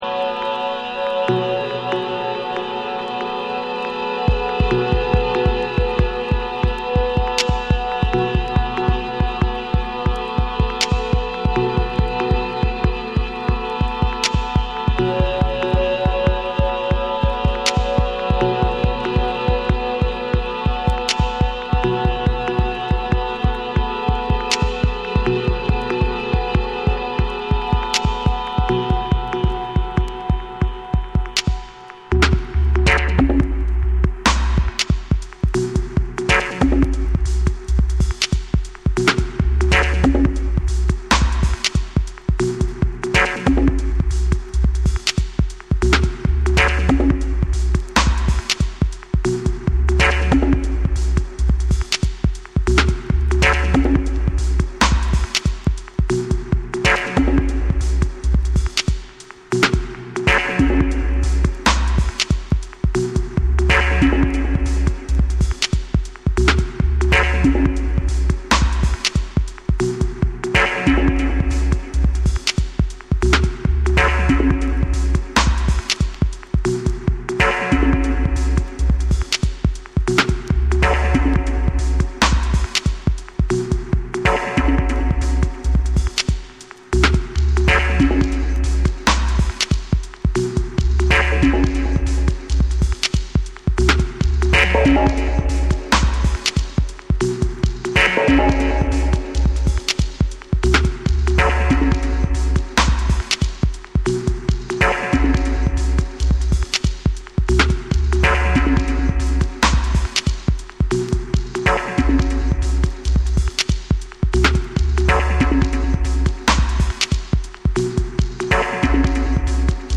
空間を活かした音響処理と緊張感のある展開が際立つディープ・トラック
BREAKBEATS / DUBSTEP